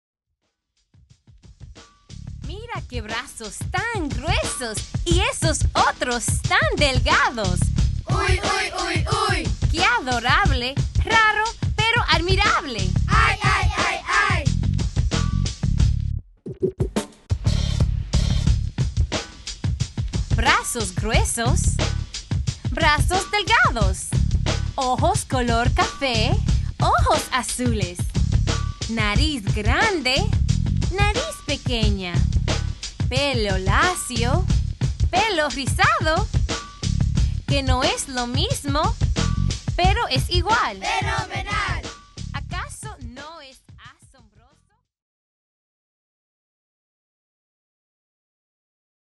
Spanish Readalongs